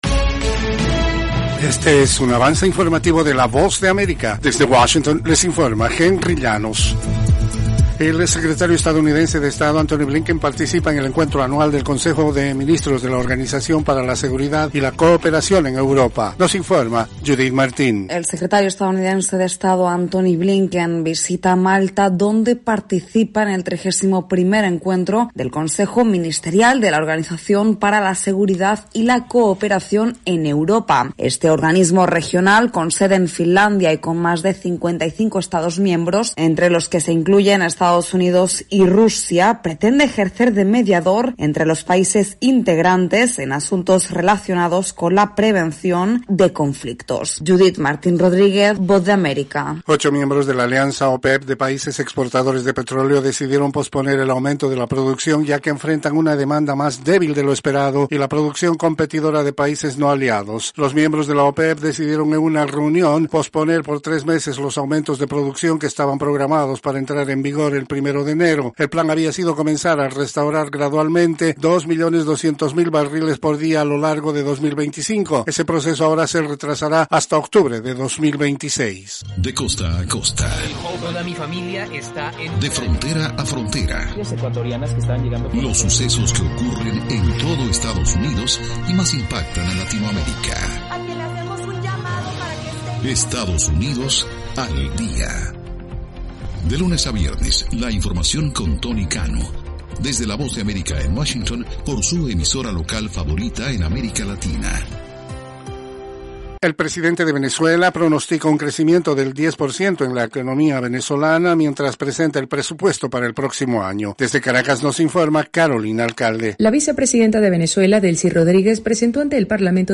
Cápsula informativa de tres minutos con el acontecer noticioso de Estados Unidos y el mundo.